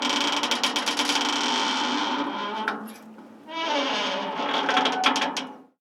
Bisagra de una puerta de metal
puerta
bisagra
chirriar
metal
Sonidos: Hogar